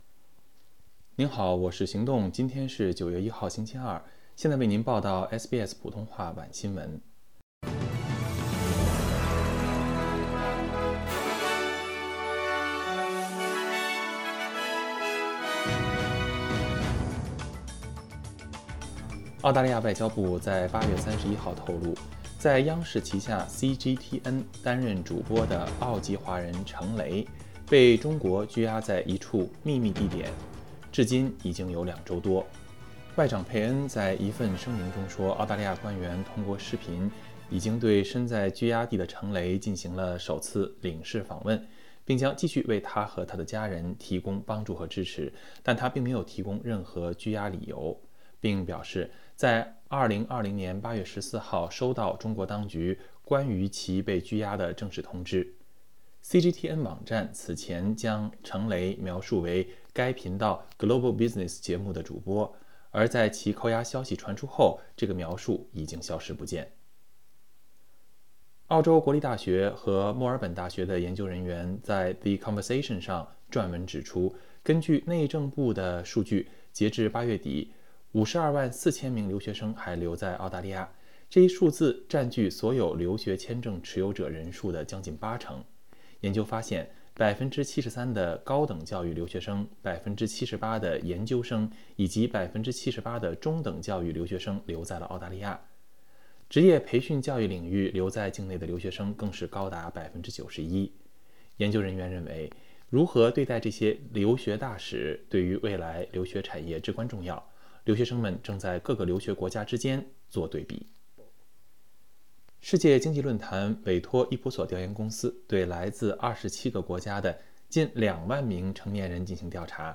SBS晚新聞（9月1日）